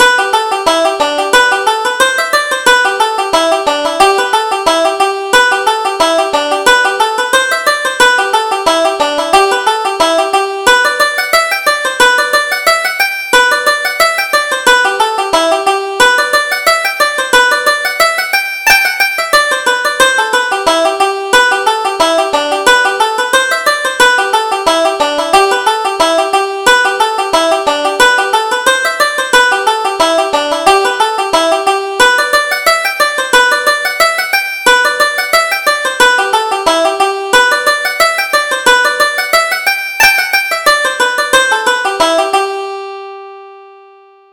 Reel: Dillon Brown